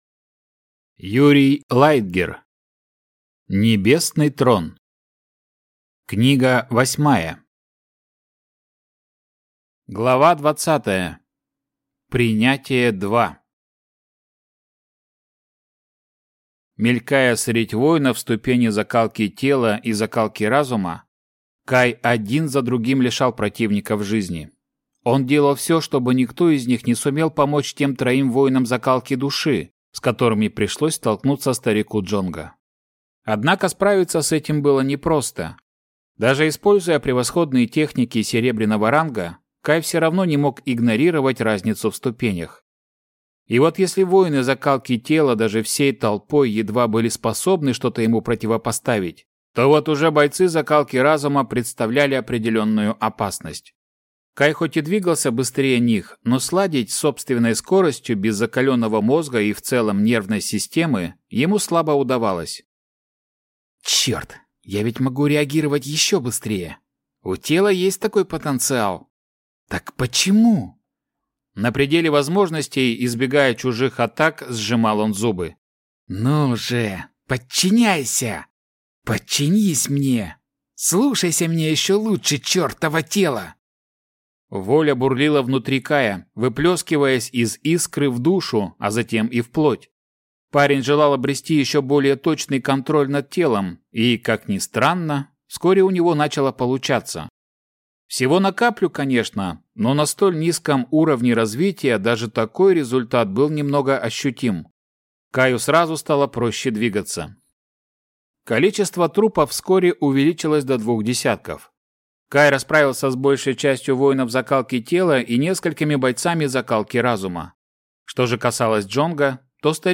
Аудиокнига Небесный Трон. Книга 8. Часть 2 | Библиотека аудиокниг